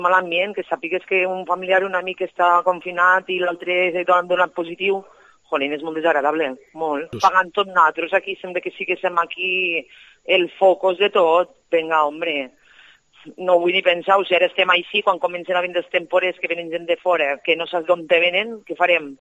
En una localidad donde todos se conocen el miedo a una enfermedad potencialmente mortal, y la tristeza se adueñan de los vecinos.